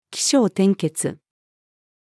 起承転結-female.mp3